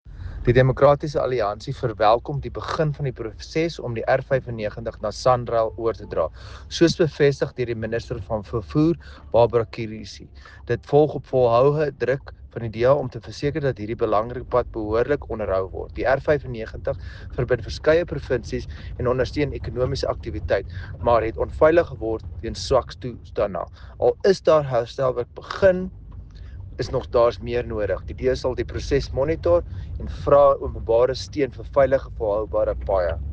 Afrikaans soundbites by Dr Igor Scheurkogel MP and